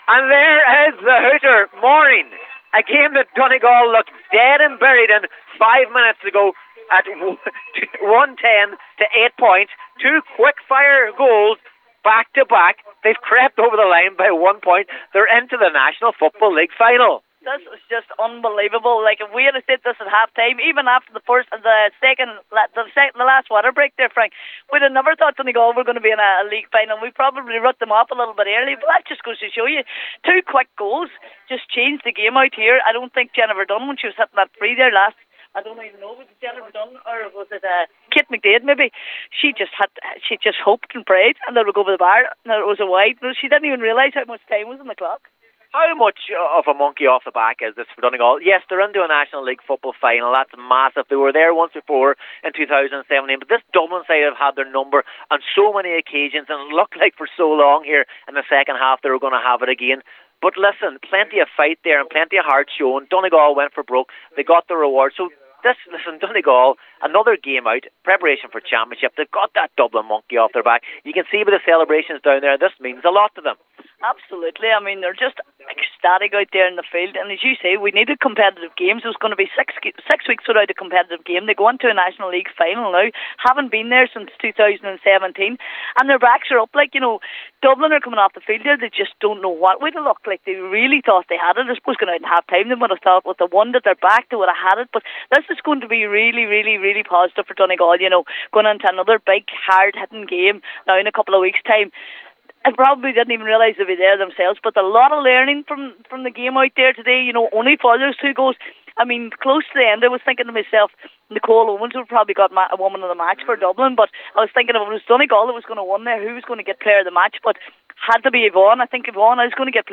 report for Highland Radio Sport…